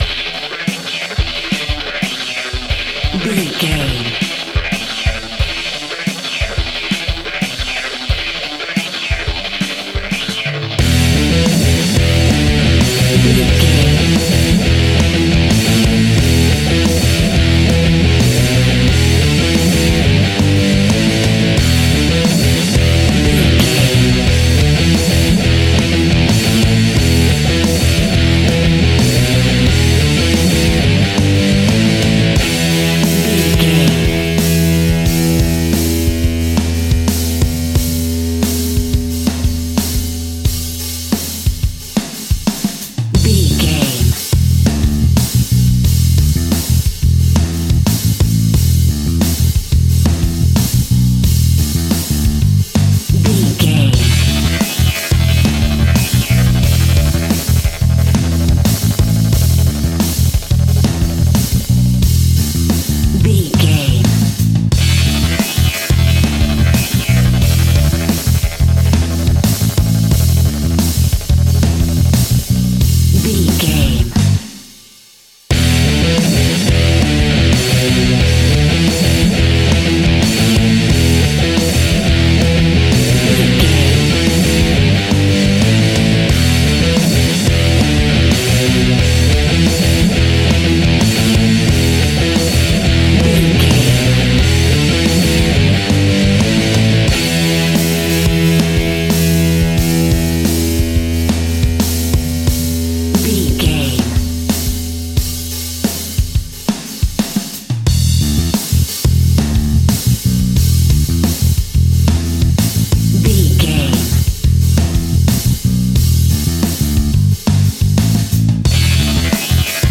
Hard and Powerful Metal Rock Music Cue Full Mix.
Epic / Action
Aeolian/Minor
hard rock
heavy metal
Rock Bass
heavy drums
distorted guitars
hammond organ